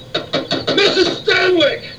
• Hilarious Quotes: (wildly pounding on door)"...MRS. STANWYK!"